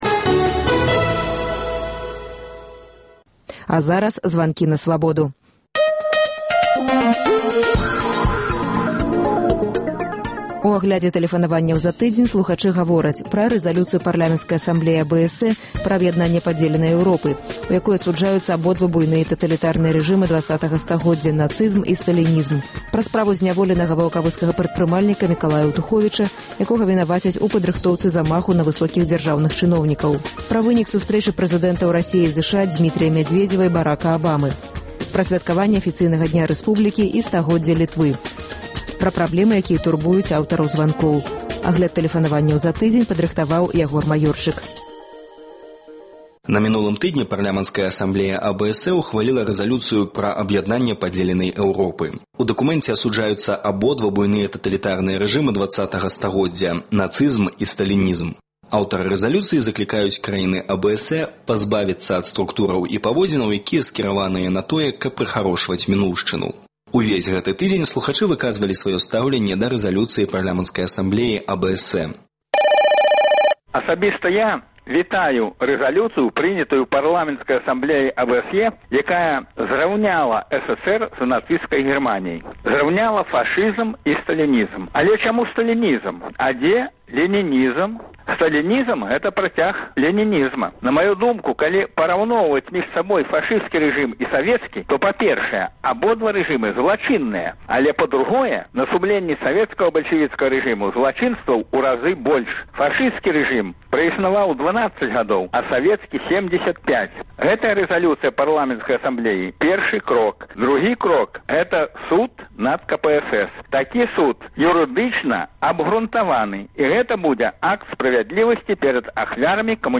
Агляд тэлефанаваньняў слухачоў